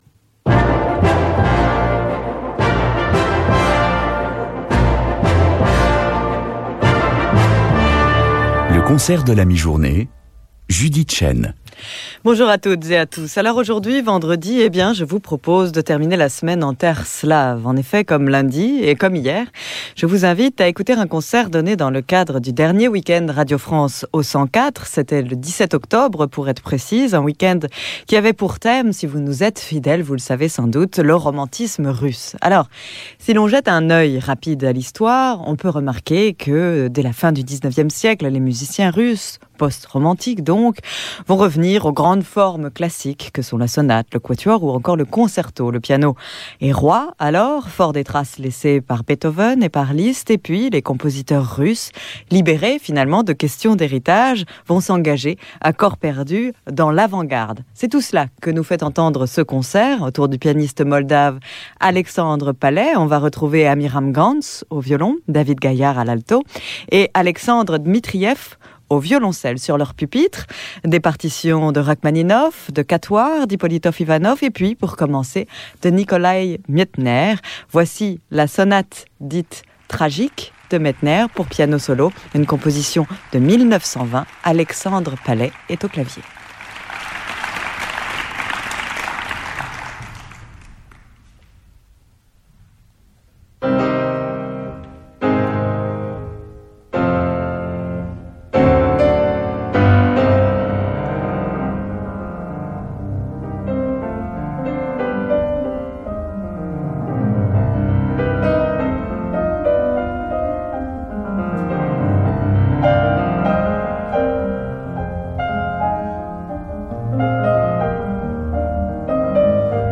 violin
viola for a concert of reasonably rare material